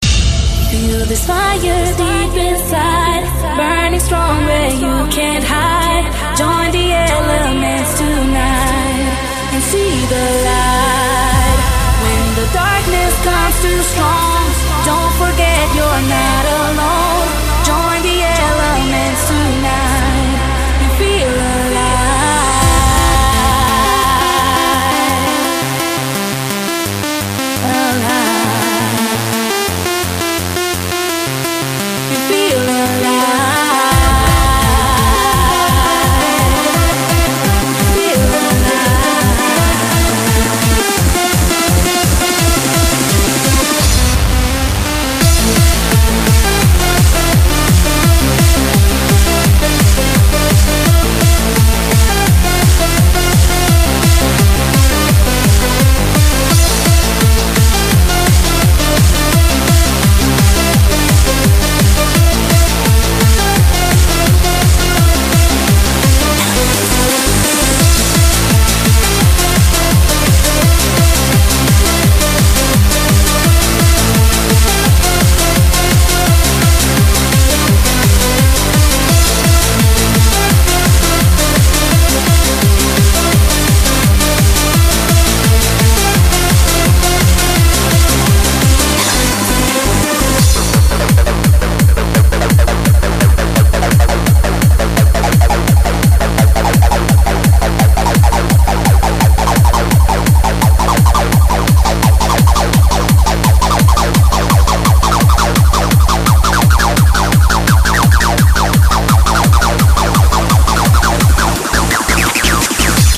BPM170
Audio QualityMusic Cut